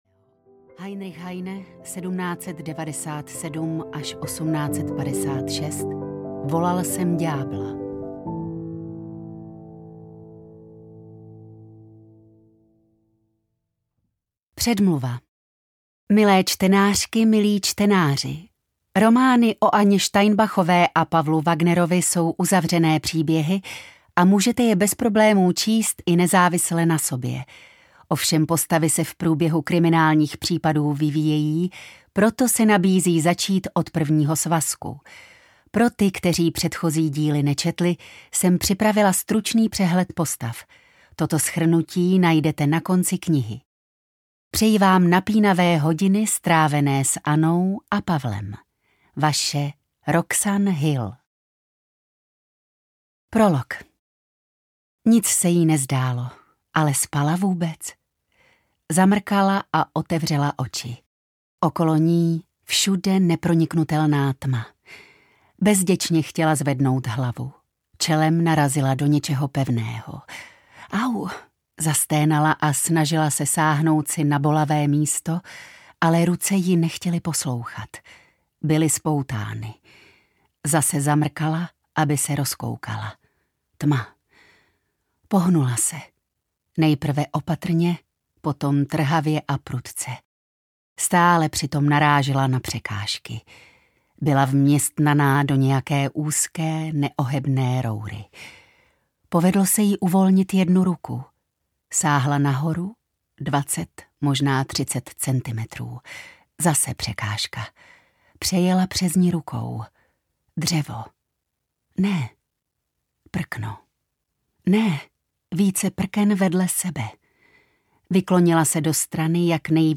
Marné prosby mrtvých audiokniha
Ukázka z knihy